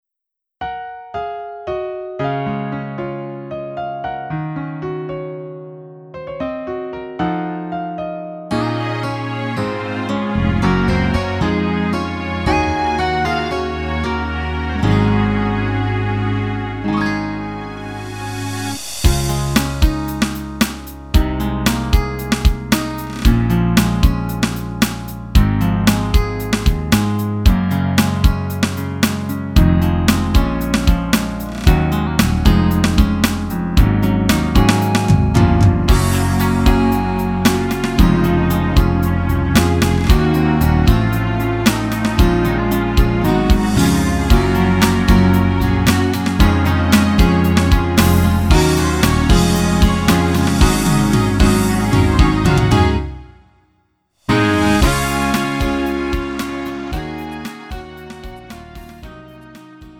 음정 원키 4:05
장르 구분 Lite MR